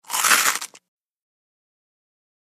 DINING - KITCHENS & EATING DRY CEREAL: INT: Dry detailed single crunches.